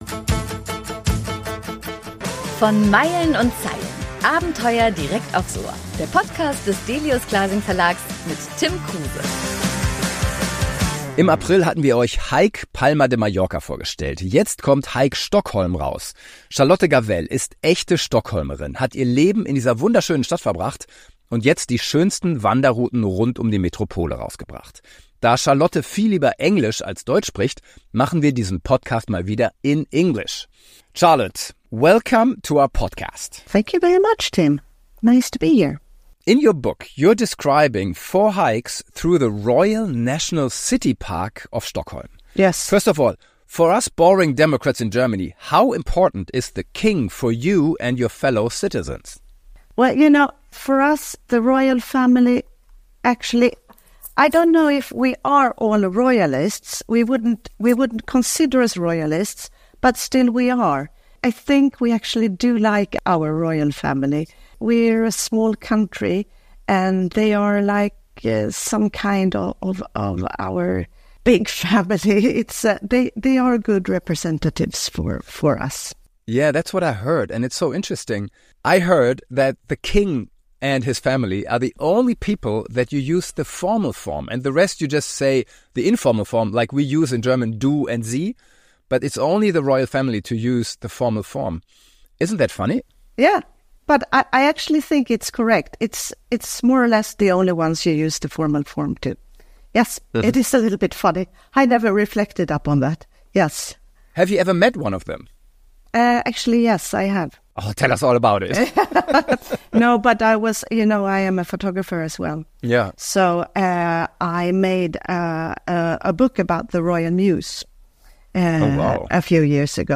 Das Interview ist auf englisch.